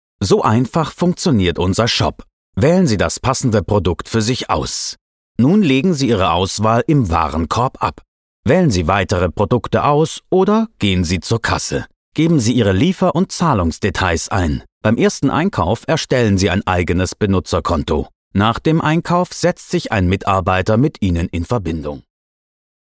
Sprecher & Schauspieler. 4 Jahre Sprech- und Schauspielausbildung am Max-Reinhardt-Seminar in Wien Mikrofonsprechen beim ORF Meine Stärken liegen sowohl in der Ruhe und im vertrauensvoll gewinnenden Ton als auch in der verspielten Gestaltung von Charakteren unterschiedlichster Couleur. Von ruhig bis schrill, von 30 bis 50.
Sprecher für Hörbuch, Werbung, Image, Off sonor, warm, souverän & facettenreich 30-50 Jahre
Sprechprobe: eLearning (Muttersprache):